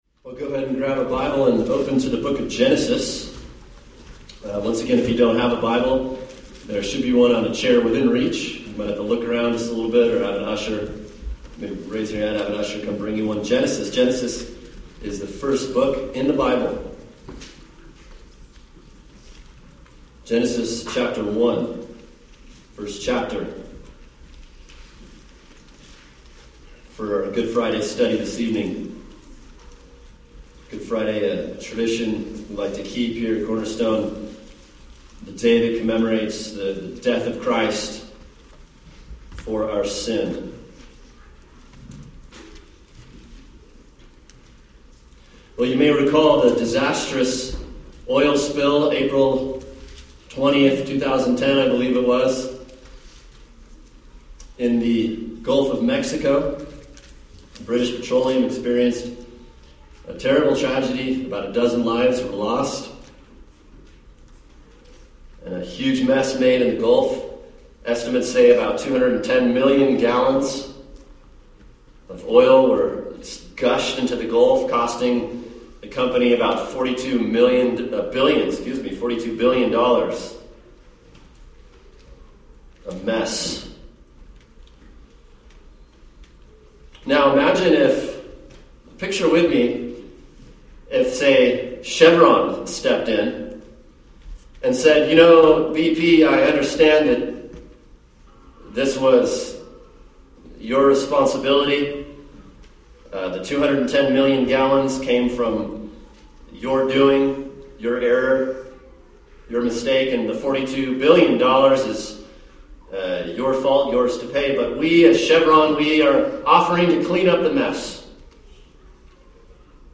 [sermon] Genesis 3:21 – His Death Covers Us | Cornerstone Church - Jackson Hole